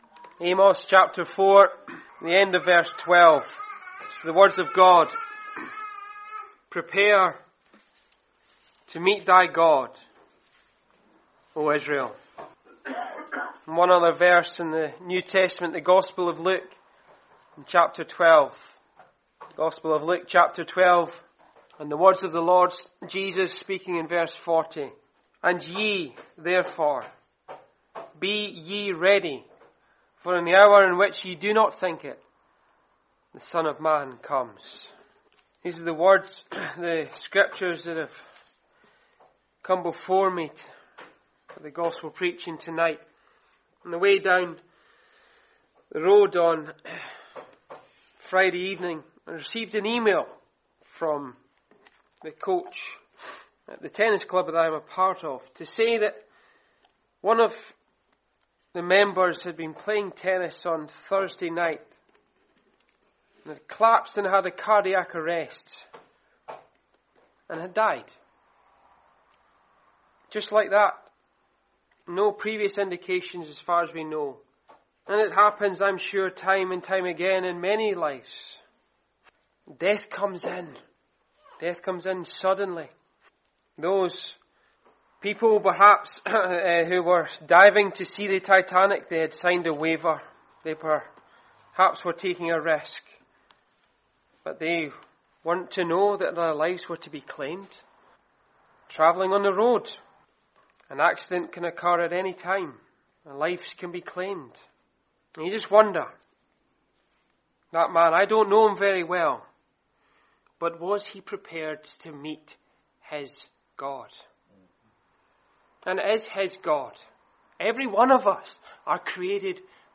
The wonderful news of the Gospel is that God has sent a Saviour, Jesus Christ who came into the world to save sinners. Listen to this Gospel message to hear what Jesus has done to save you.